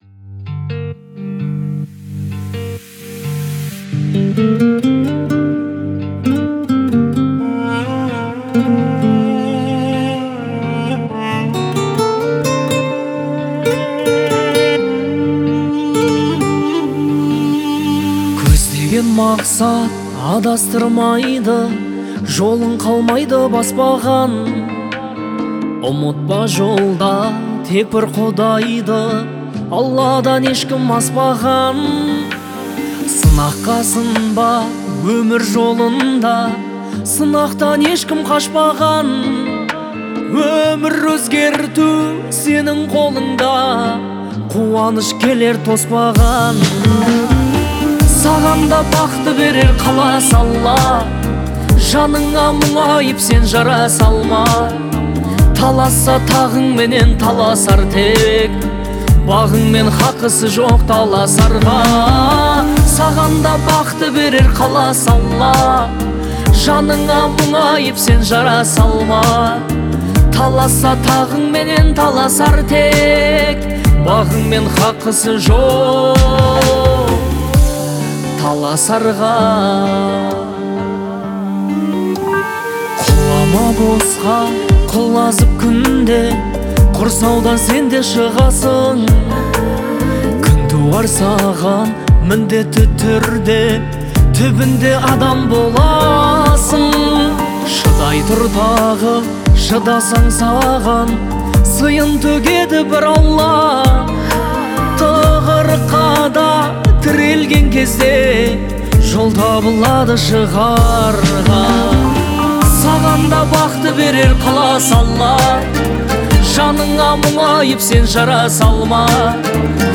Категория: Казахские,